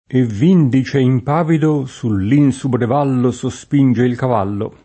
e vv&ndi©e imp#vido Sull &nSubre v#llo SoSp&nJe il kav#llo] (Zanella) — un es. poet. di pn. piana: Col duca sfortunato degl’Insubri [